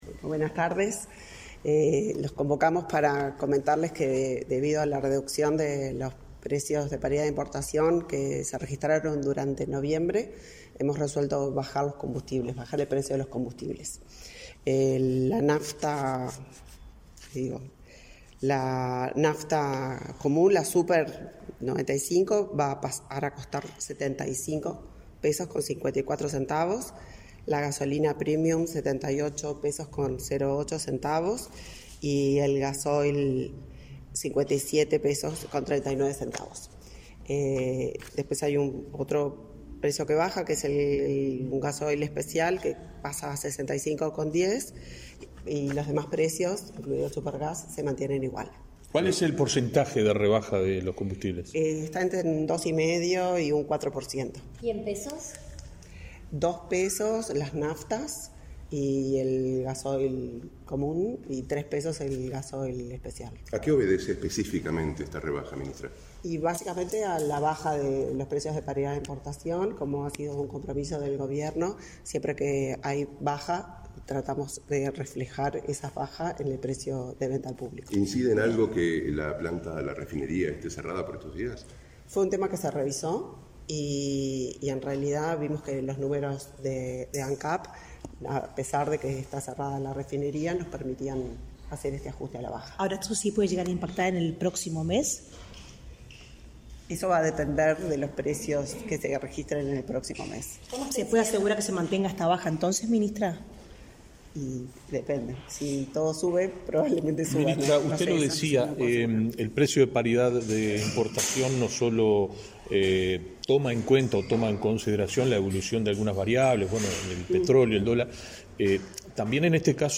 Palabras de la ministra del MIEM, Elisa Facio
La ministra Elisa Facio realizó una conferencia de prensa.